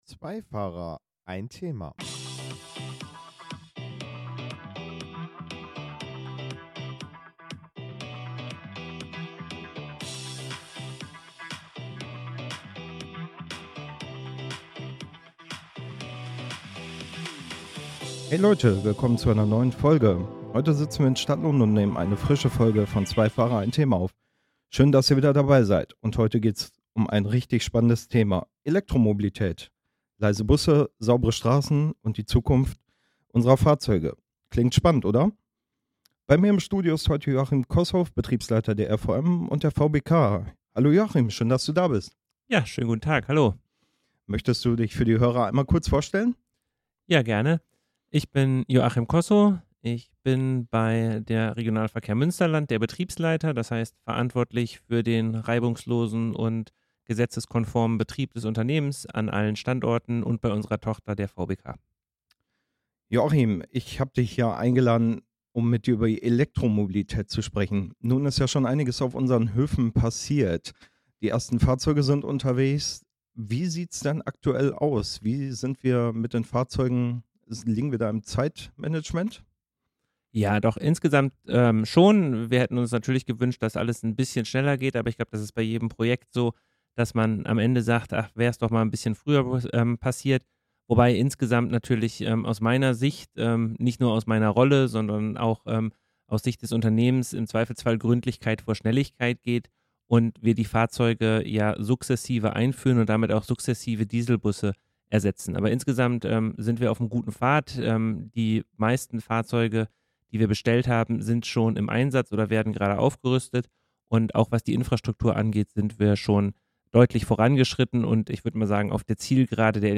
Warum E-Busse nicht nur ressourcenschonend, sondern auch wartungsfreundlicher sind, und welche Chancen die Zukunft bringt wird in unserer Podcast-Folge in gewohnt lockerem und verständlichem Stil verraten. Auch kritische Fragen, etwa zur Reichweite oder zu Sicherheitsaspekten, werden nicht ausgespart.